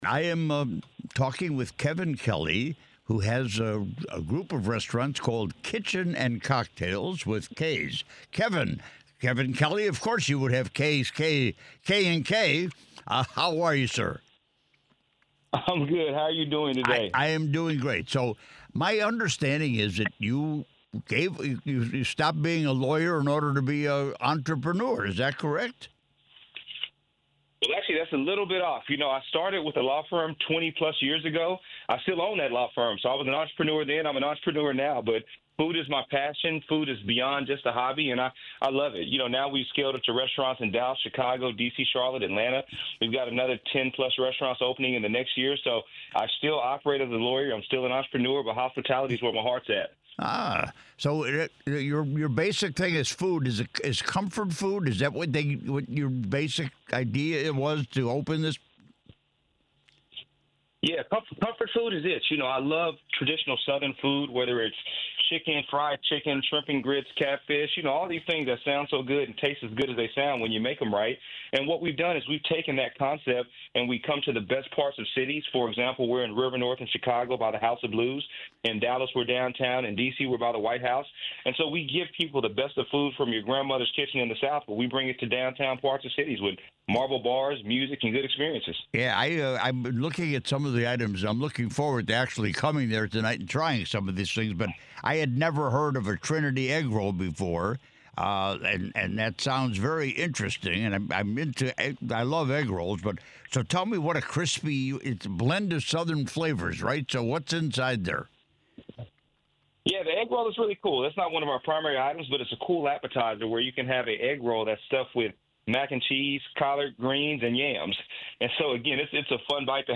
Here is the interview: